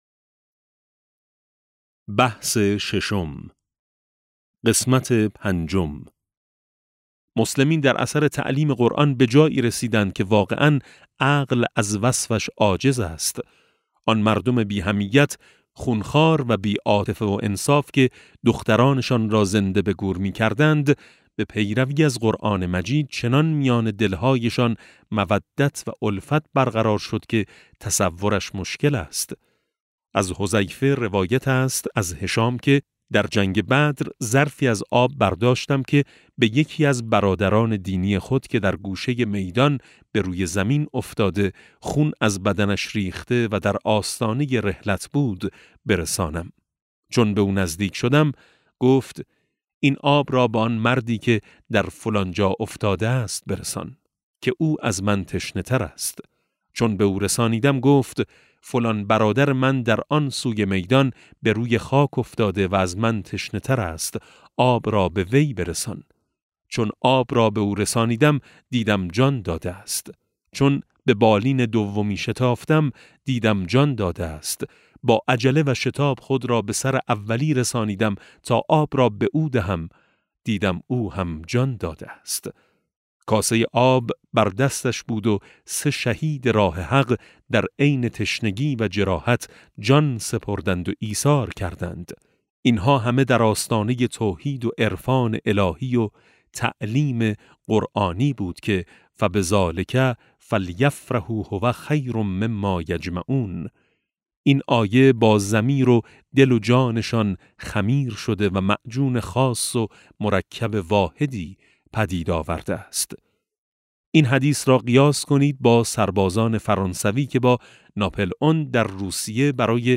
کتاب صوتی نور ملکوت قرآن - ج3 ( 19 تعداد فایل ها ) | علامه طهرانی | مکتب وحی